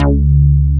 FKBASSG2.wav